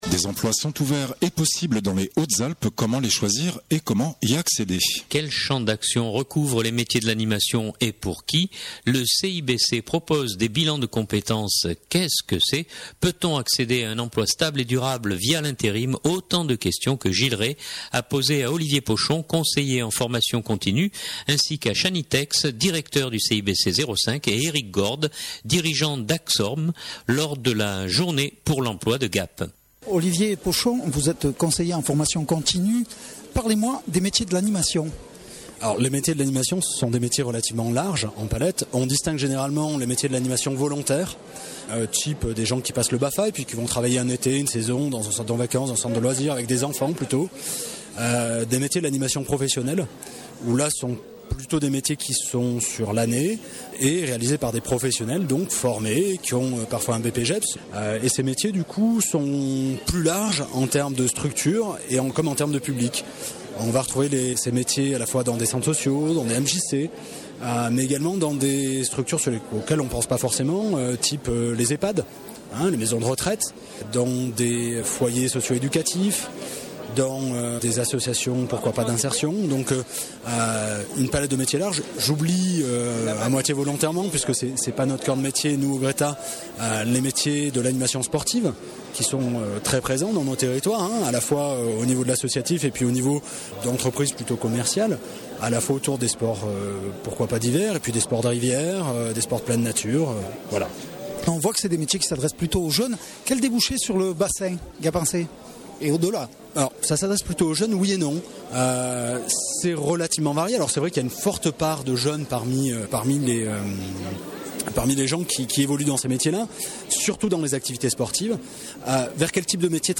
lors de la journée pour l’emploi de Gap